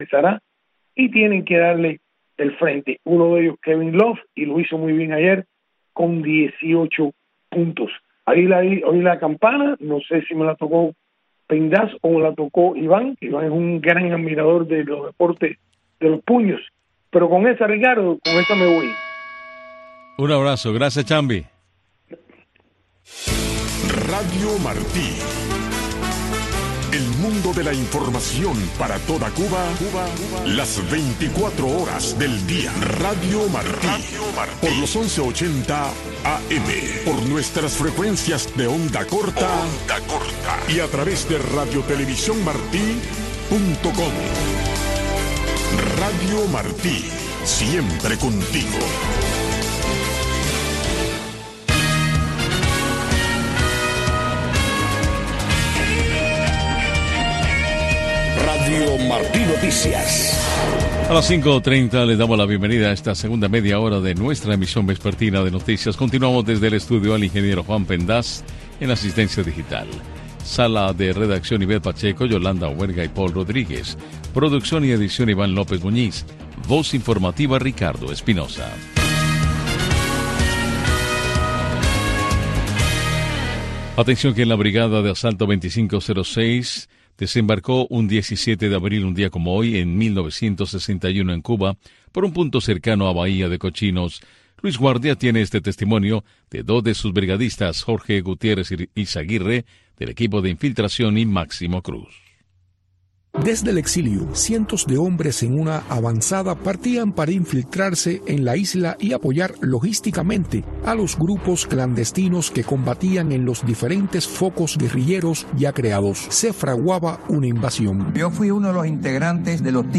Noticiero Radio Martí presenta los hechos que hacen noticia en Cuba y el mundo